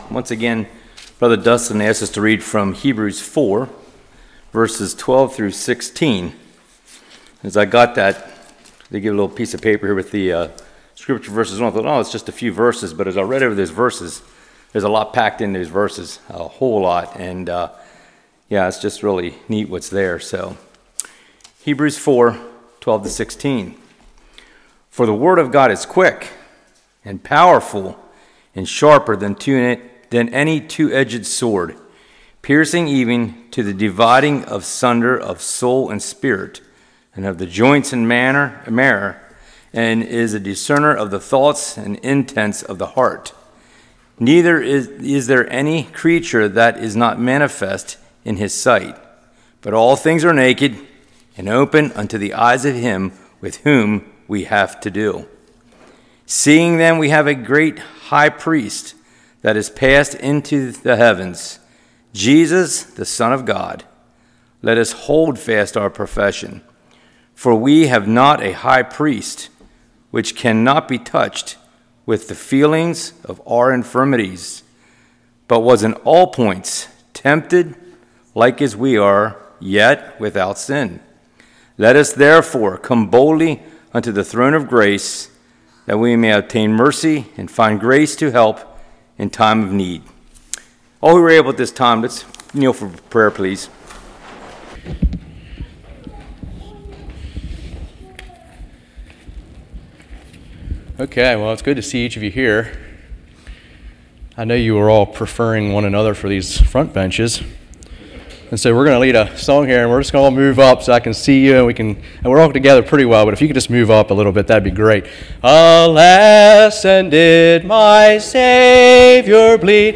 Hebrews 4:12-16 Service Type: Evening No live stream.